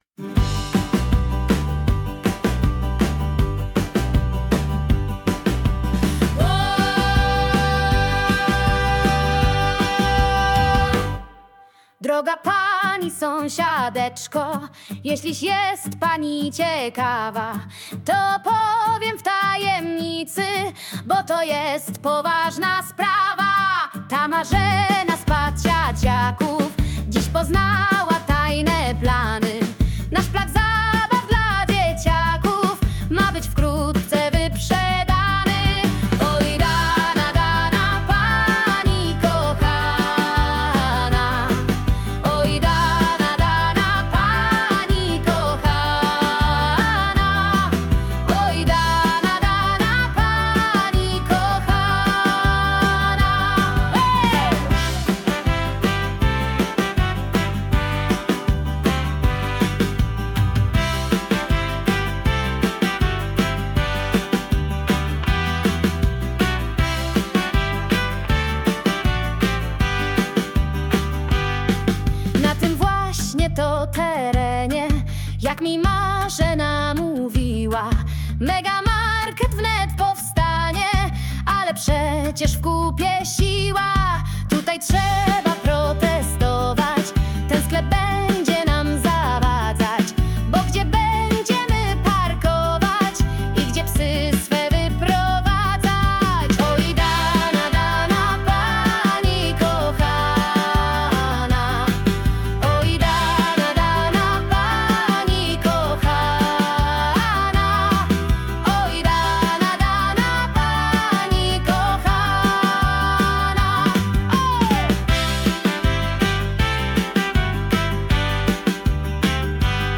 'Plac zabaw'. Piosenka satyryczna.